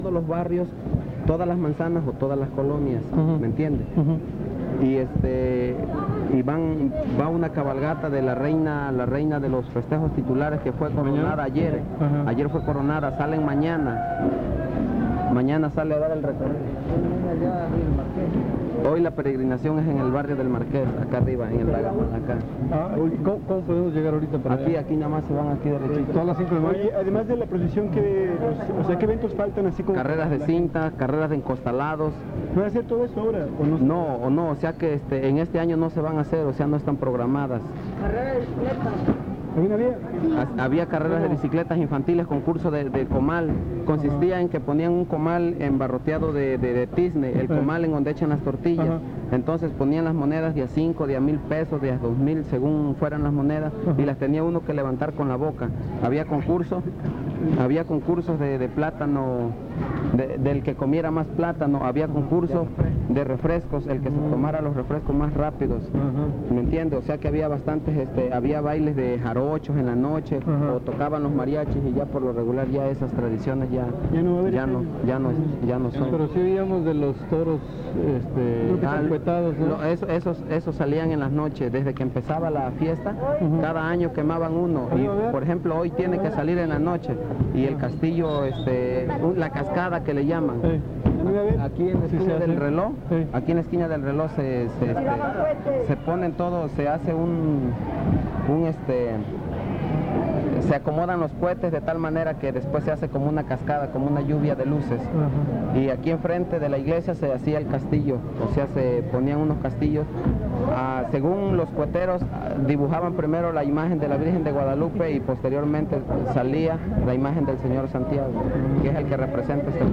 03 Participantes en la quema de muñecos